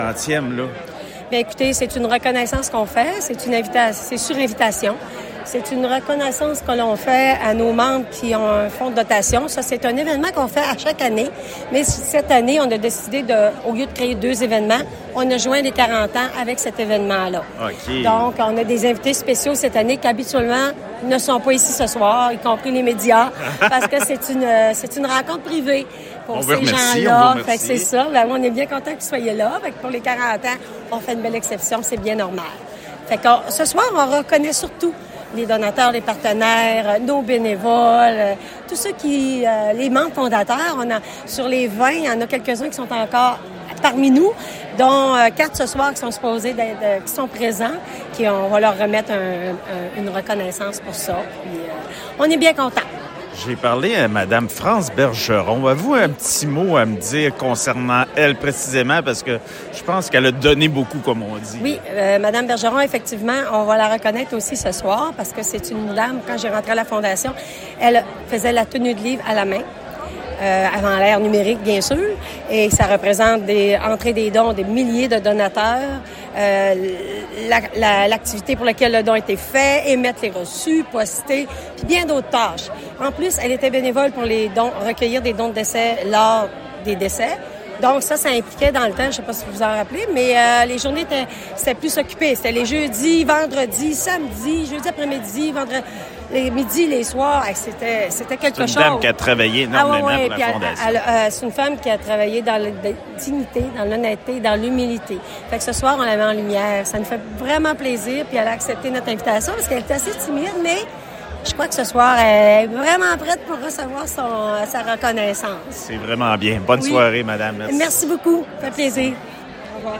La Fondation de l’Hôpital de La Malbaie a célébré son 40e anniversaire, hier soir, lors d’un cocktail dînatoire au Pavillon Joseph-Rouleau du Domaine Forget.